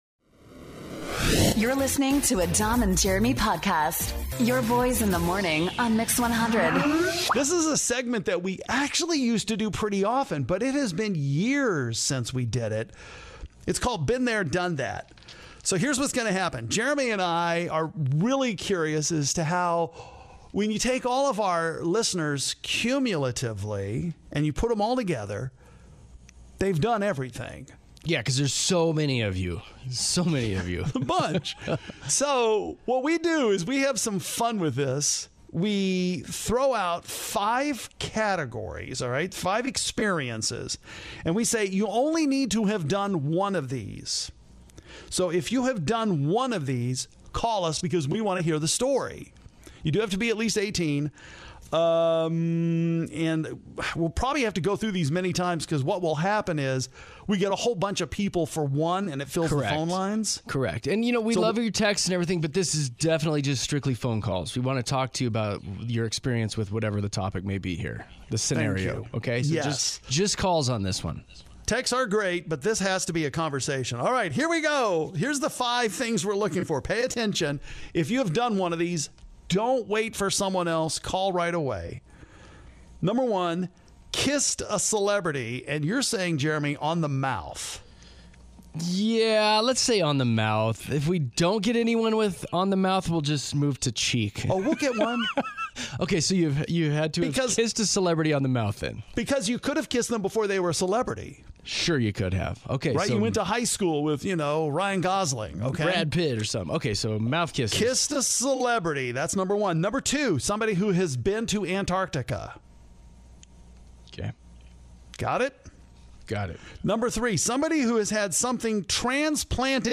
We ask you, the listener, call in to us to talk about five very specific things you have done!